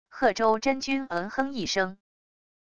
鹤舟真君嗯哼一声wav音频